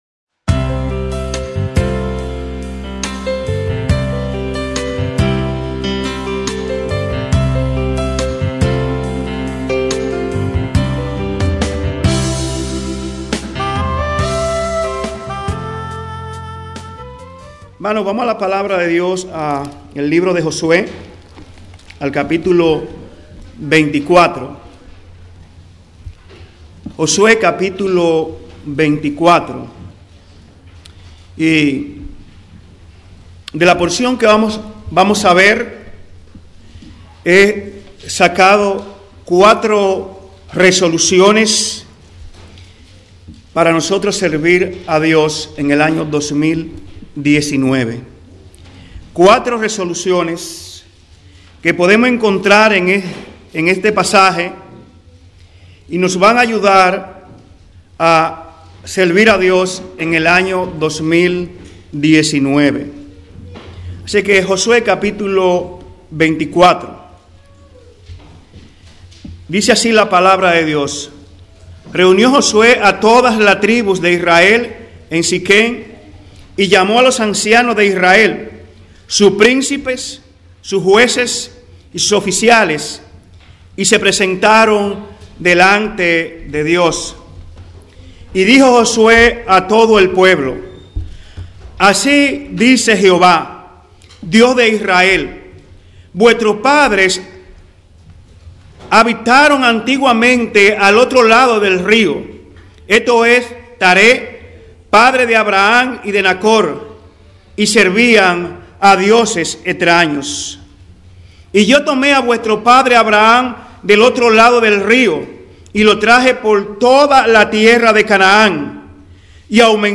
Templo Bíblico Providence